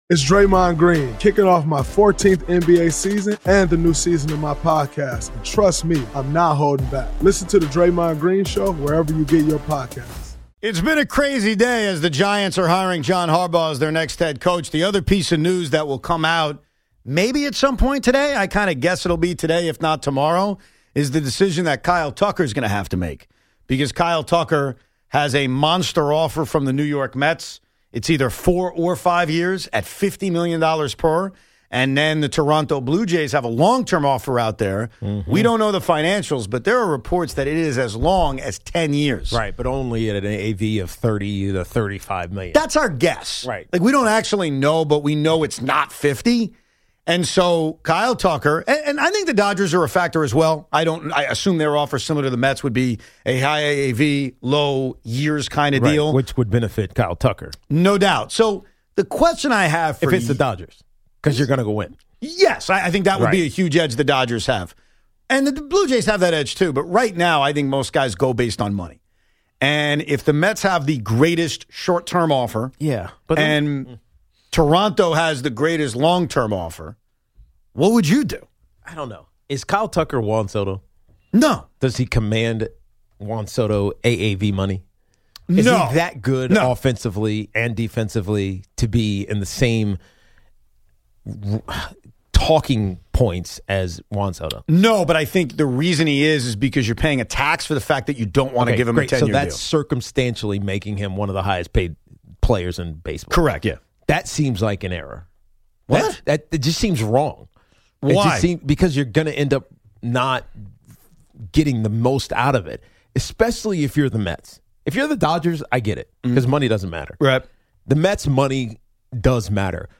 Evan Roberts and Tiki Barber react to the Giants hiring John Harbaugh as their next head coach and break down what it means for Big Blue moving forward. Plus, the guys dive deep into the Kyle Tucker sweepstakes, debating the Mets’ reported short-term mega offer versus Toronto’s long-term deal, whether Tucker is worth $50 million per year, and what decision makes the most sense for both the player and the Mets. Giants history, Mets roster construction, Yankees crossover fallout, and plenty of classic Evan & Tiki banter all in one jam-packed segment on WFAN.